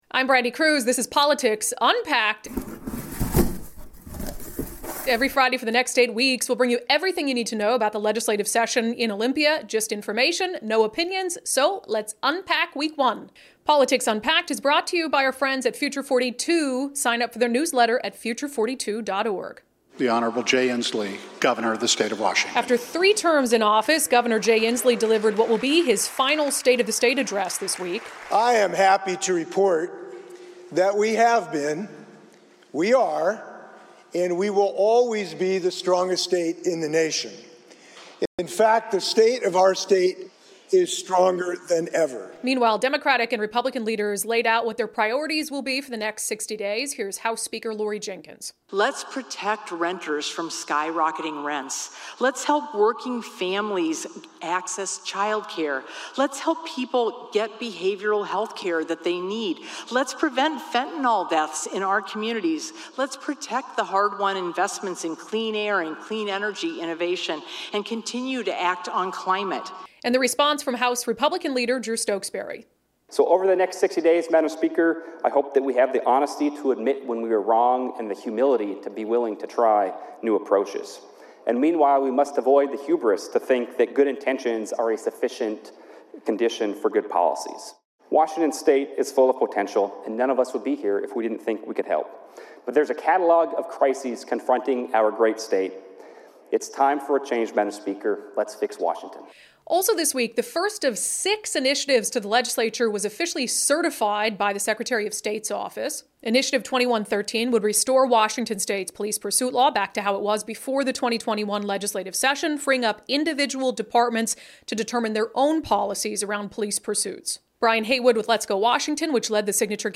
Here is everything you need to know about what happened this week in Olympia. Guest: State Sen. Mark Mullet (D-Issaquah).